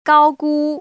[gāogū] 까오구  ▶